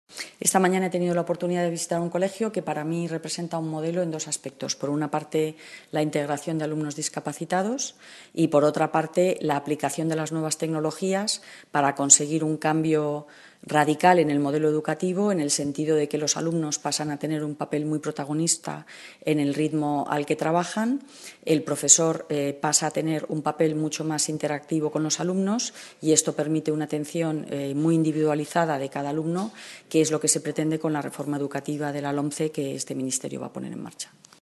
Palabras de Montserrat Gomendio Salto de línea